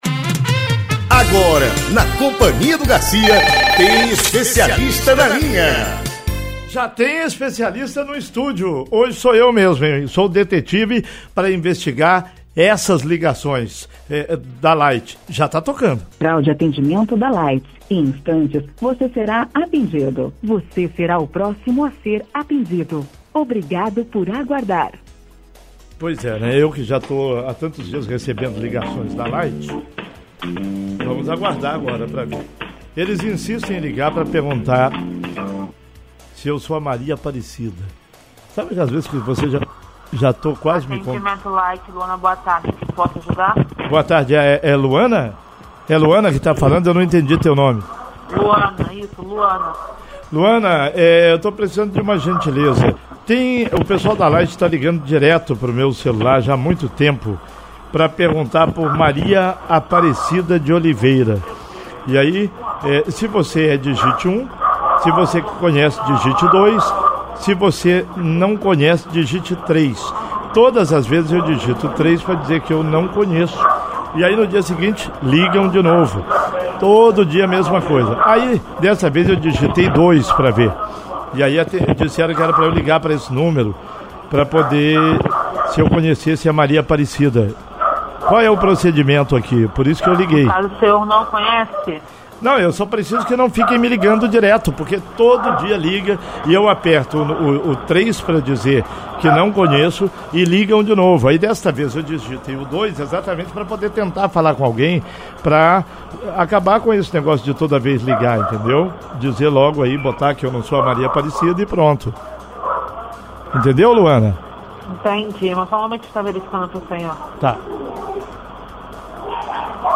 Cansado de receber tanta ligação, o apresentador resolveu ligar para a empresa ao vivo.